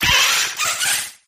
sizzlipede_ambient.ogg